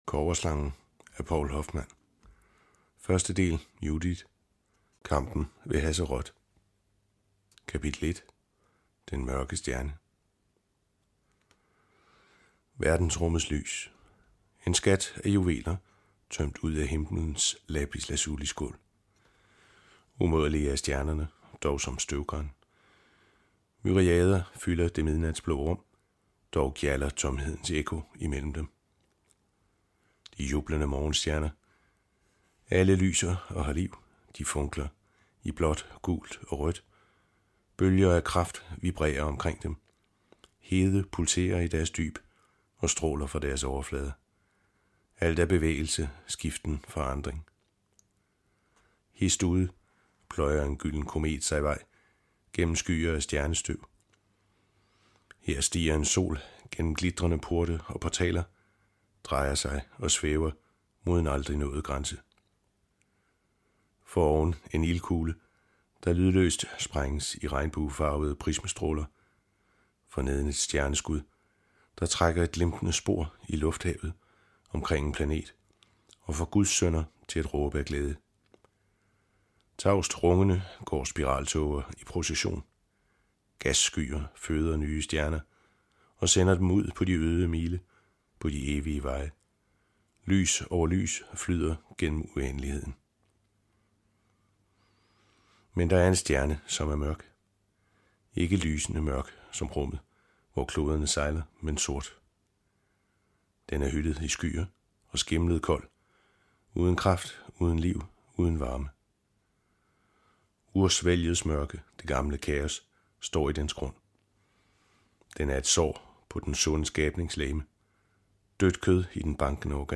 Hør et uddrag af Kobberslangen Kobberslangen Moses III Format MP3 Forfatter Poul Hoffmann Lydbog E-bog 149,95 kr.